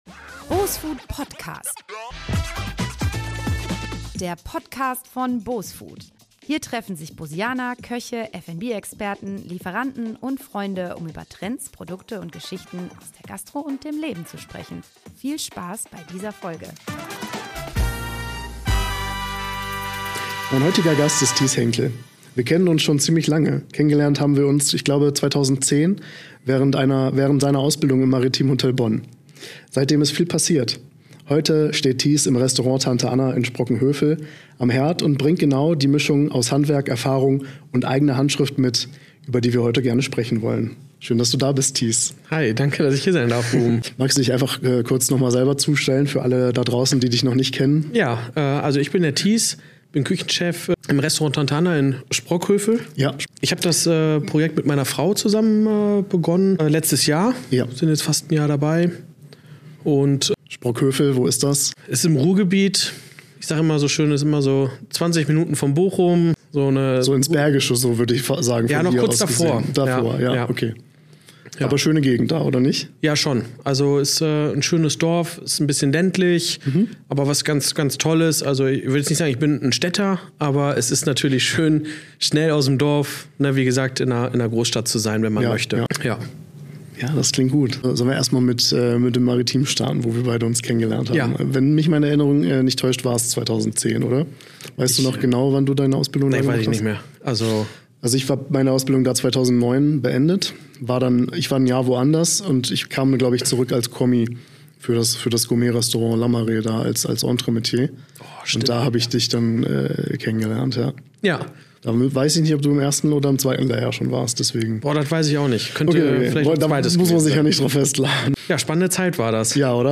Ein ehrliches Gespräch über Sterneküchen und den Weg in die Selbstständigkeit.